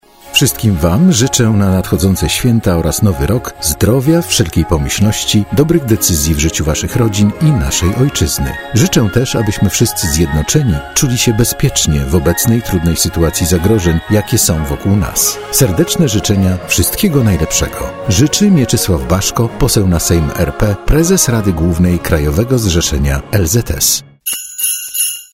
Poseł Mieczysław Baszko za pośrednictwem naszego portalu składa wszystkim mieszkańcom naszego regionu świąteczne życzenia.